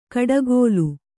♪ kaḍagōlu